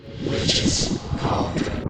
get_cauldron.ogg